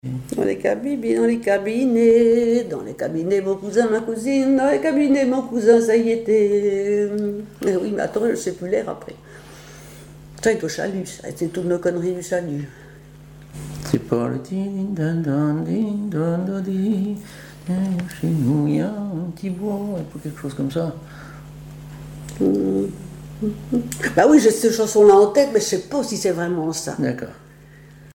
Couplets à danser
Chansons et commentaires
Pièce musicale inédite